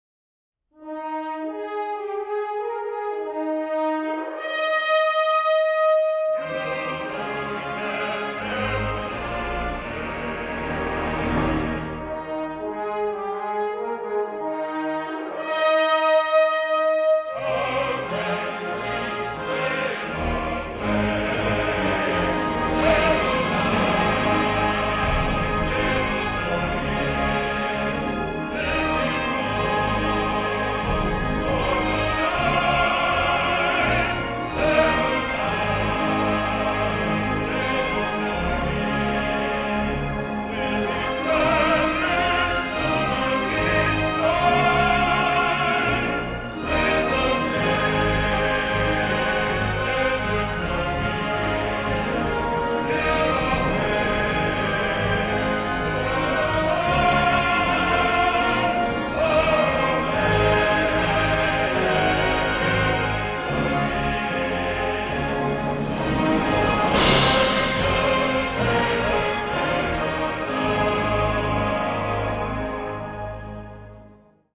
baritone.